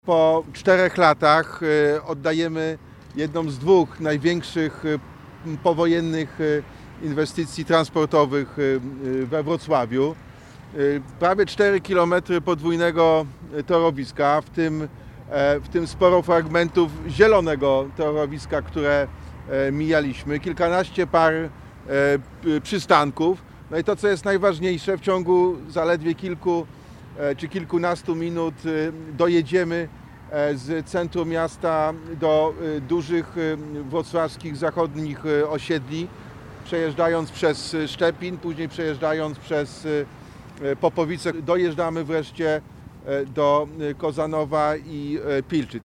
Wypadły bardzo dobrze – mówi Jacek Sutryk, prezydent Wrocławia.